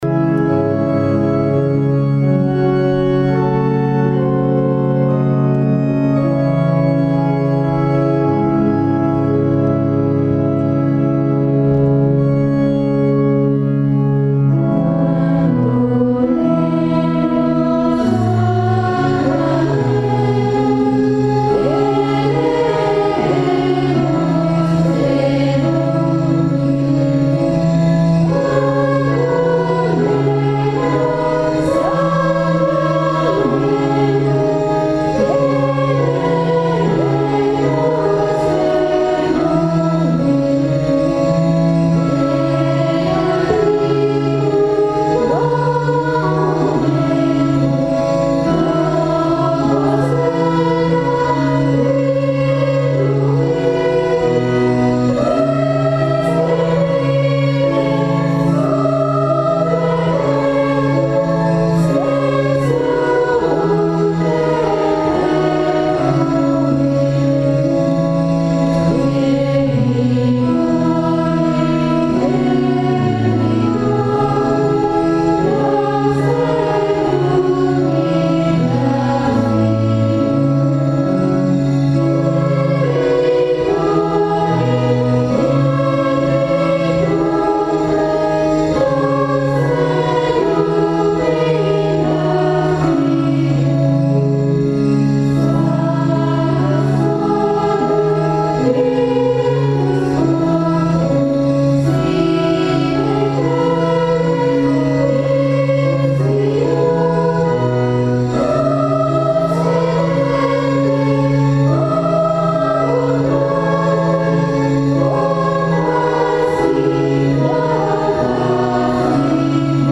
En modalidad incaica